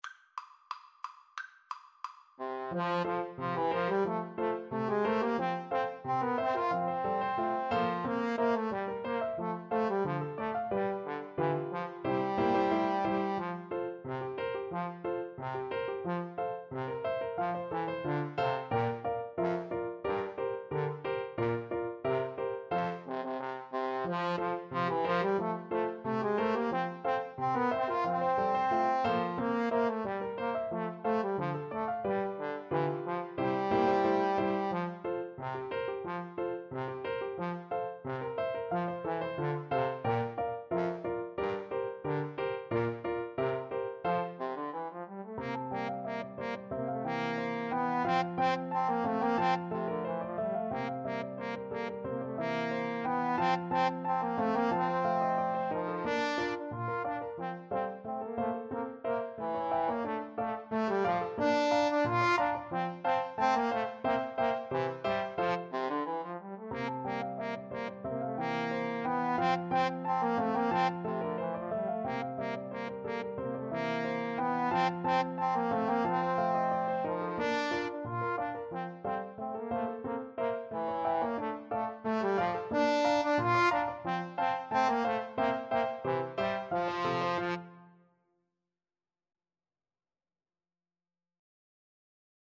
Trombone Duet  (View more Intermediate Trombone Duet Music)
Traditional (View more Traditional Trombone Duet Music)